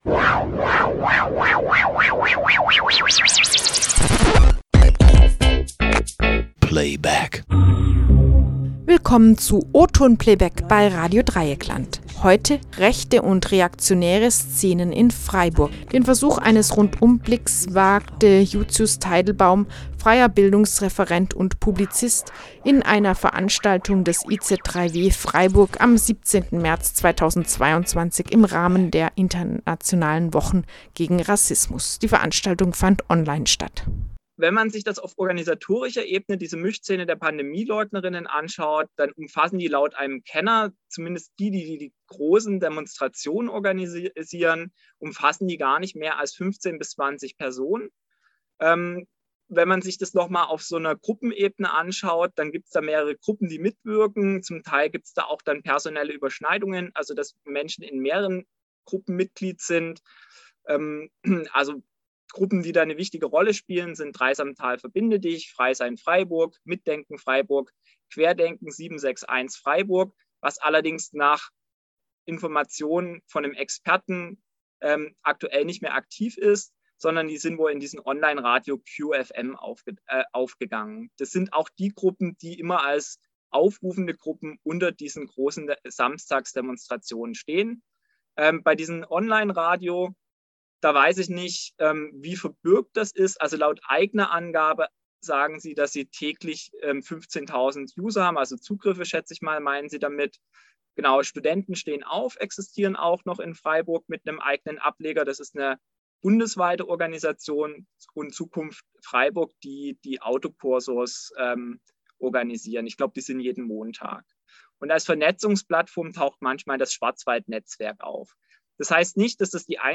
Für die Sendung mussten wir den Vortrag leicht kürzen - in voller Länge könnt Ihr den Mitschnitt hier hören: 71:19
Eine Veranstaltung des iz3w Freiburg vom 17. März 2022 im Rahmen der Internationalen Wochen gegen Rassismus.